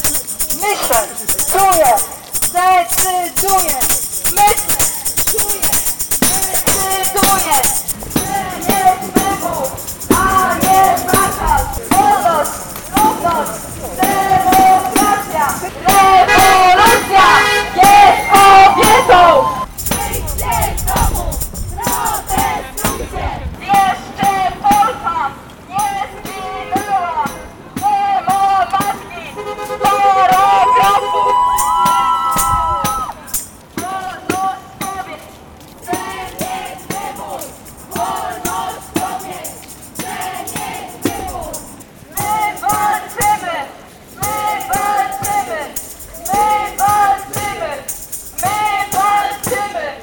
Nie zabrakło skandowanych haseł, podkreślających prawa kobiet, wolność wyboru, ale też krytycznie oceniających działania rządu: